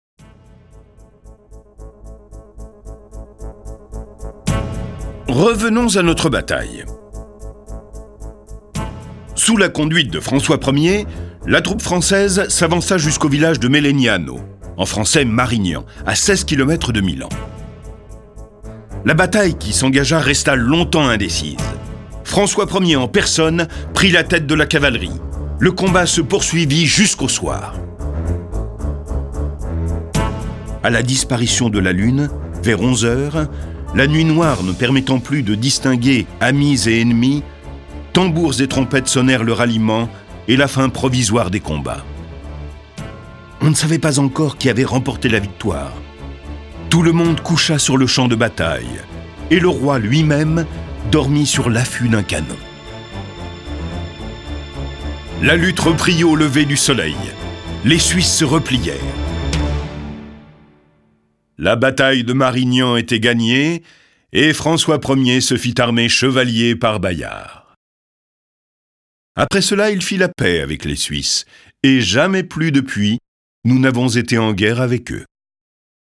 Diffusion distribution ebook et livre audio - Catalogue livres numériques
Cette version sonore de ce récit est animée par neuf voix et accompagnée de plus de trente morceaux de musique classique.